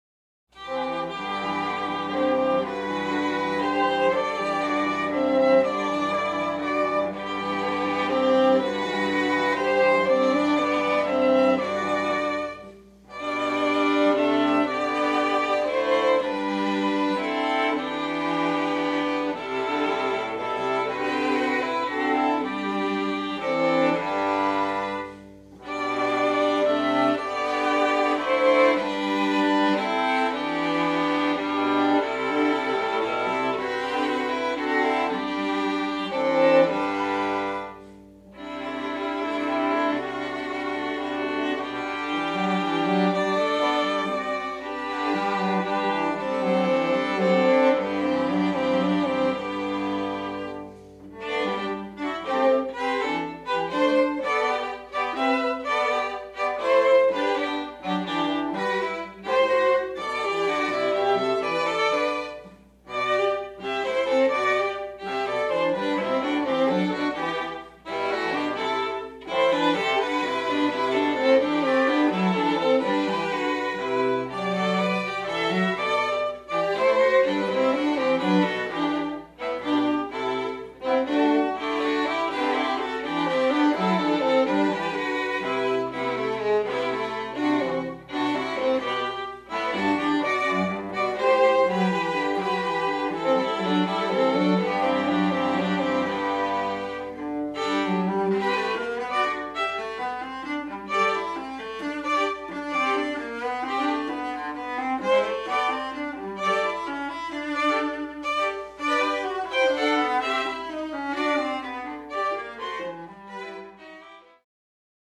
Voicing: String Trio